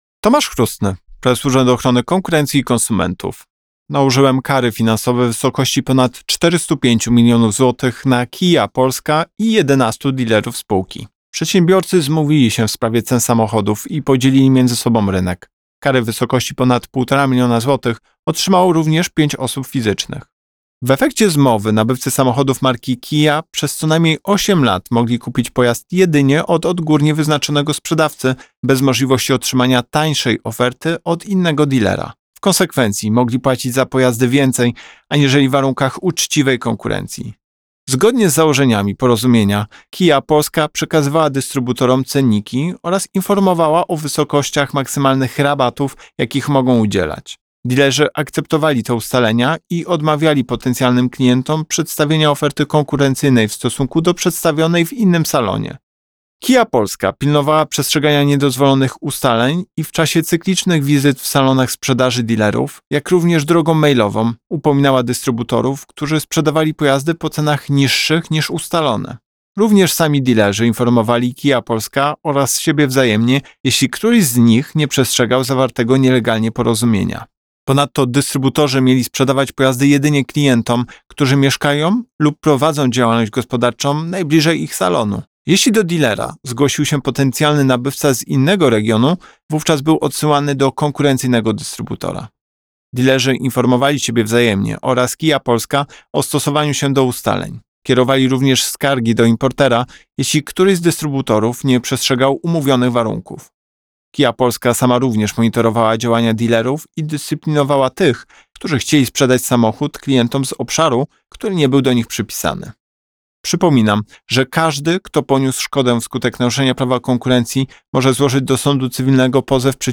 Wypowiedź Prezesa UOKiK Tomasza Chróstnego z 1 października 2024 r..mp3